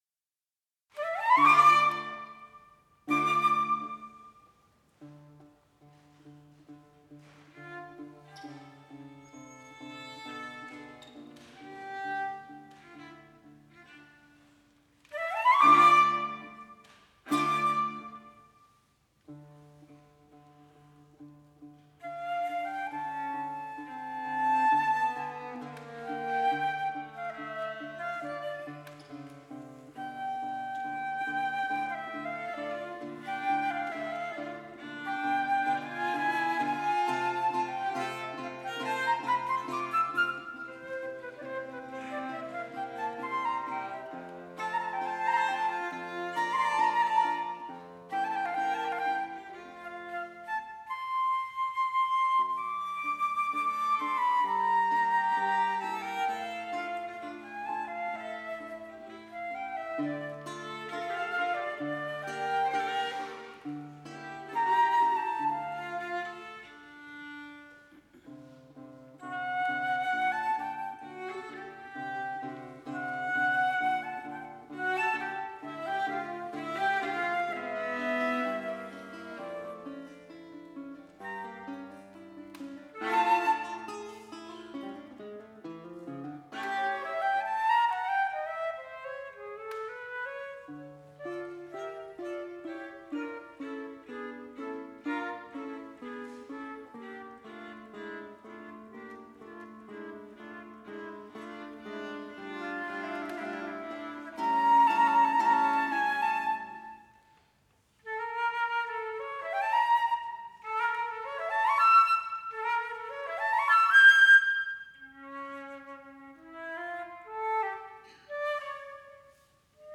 flauto
viola
chitarra
Cappella dei Mercanti, Torino
Andante - Allegro non tanto e moderato con gusto
Torino. Novembre 2008. Live recording.